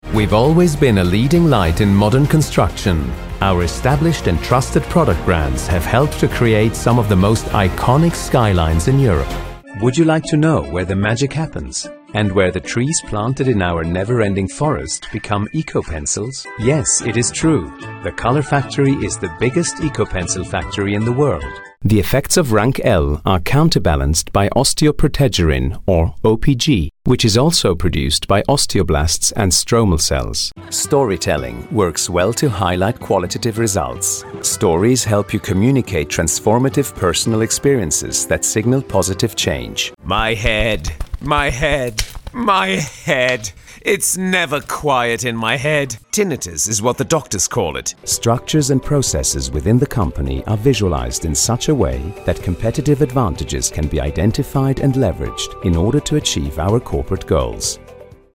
Male
Assured, Authoritative, Character, Confident, Engaging, Friendly, Gravitas, Natural, Reassuring, Smooth, Warm, Versatile
English with Eurpean/International accent (native), English with German, Italian, Swiss German, French, Spanish, Brazilian Portuguese, Russian accent, German/Italian/French/Spanish/Russian with same accents
Microphone: Neumann TLM 103